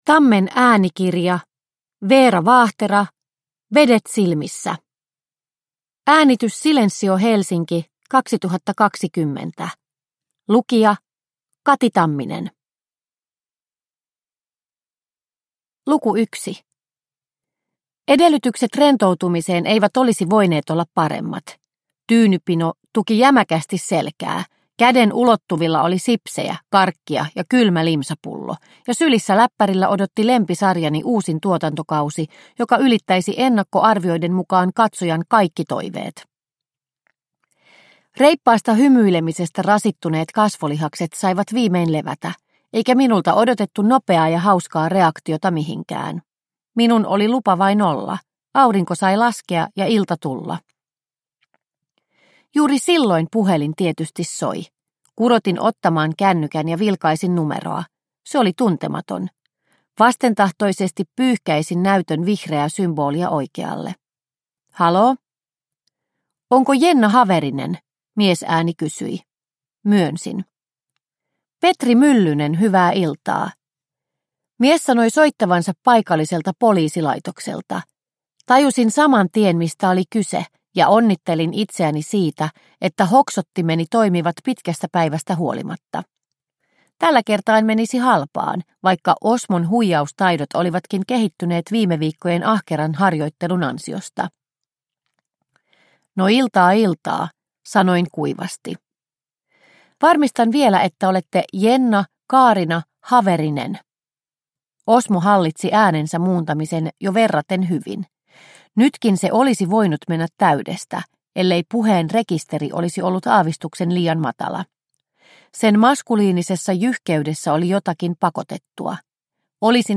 Vedet silmissä – Ljudbok – Laddas ner